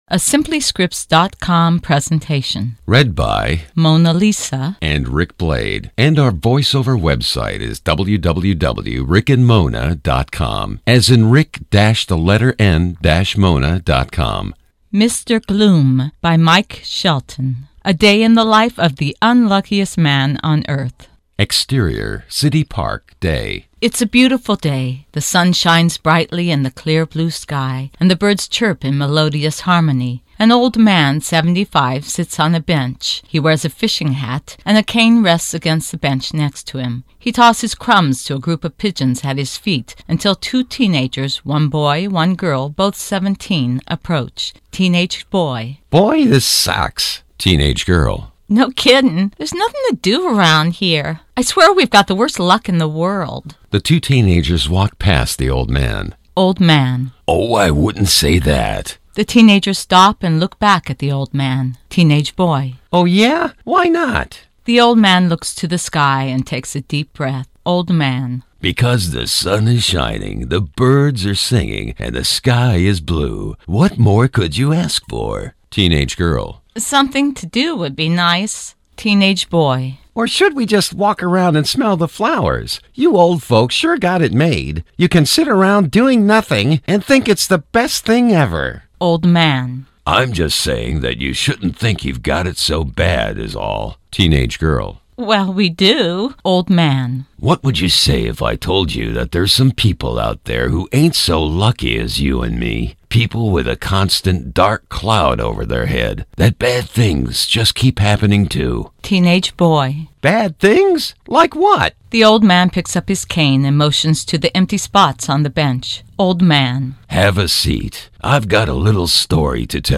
Read by Macblade Productions : She and He Voice Overs A day in the life of the unluckiest man on Earth. Short, Comedy Rated PG Read the script (14 pages pdf format).